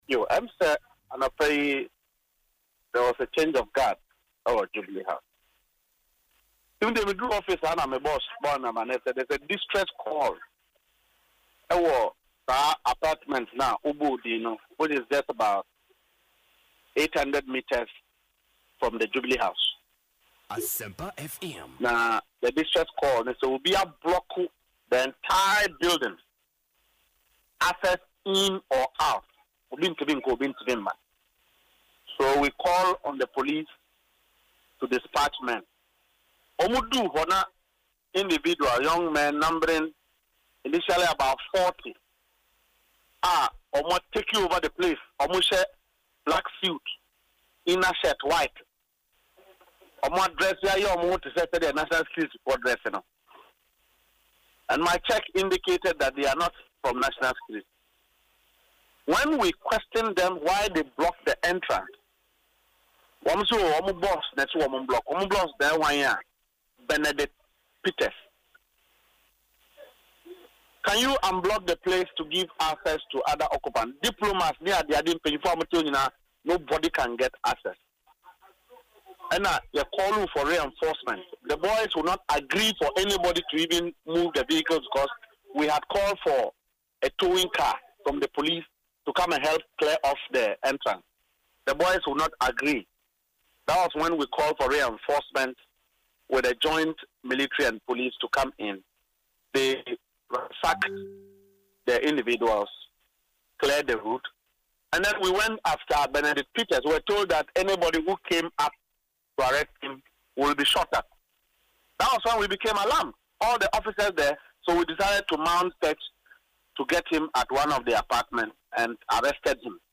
The Deputy Director of Operations at the Presidency, Mustapha Gbande, confirmed the incident during an interview on Asempa FM’s Ekosii Sen programme.